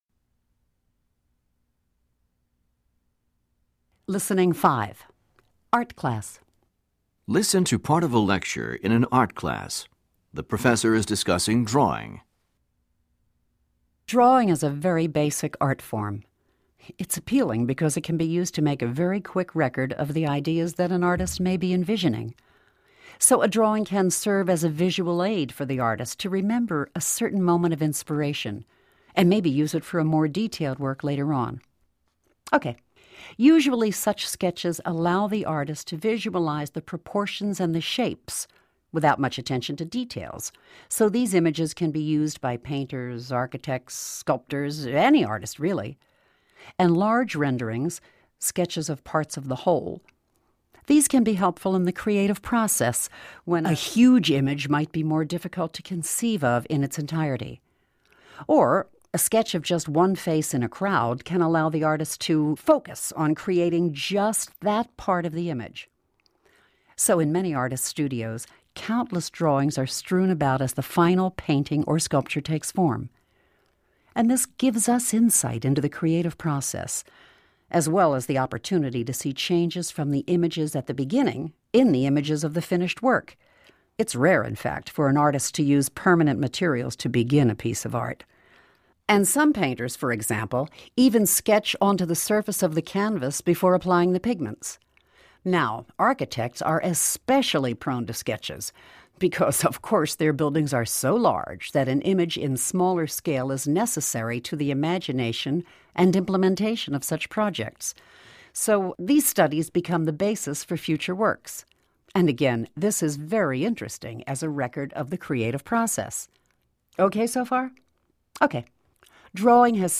Listening 3 "Psychology Class"